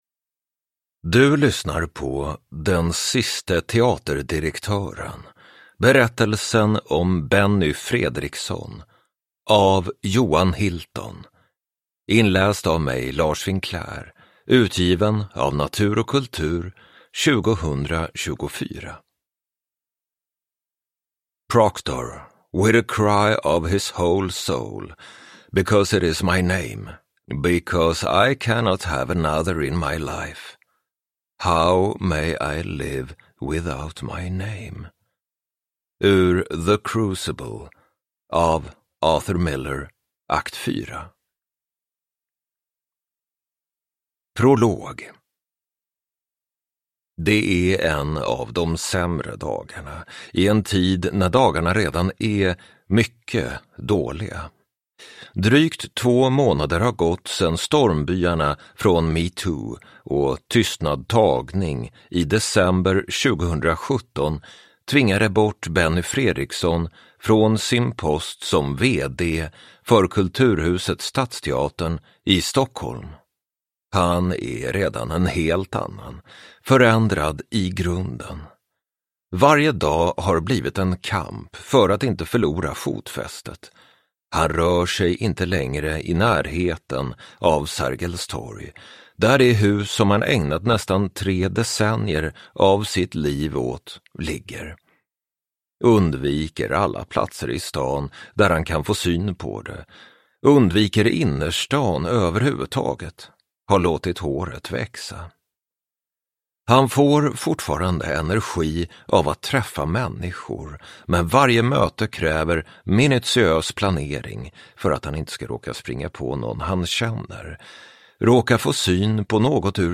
Den siste teaterdirektören : berättelsen om Benny Fredriksson / Ljudbok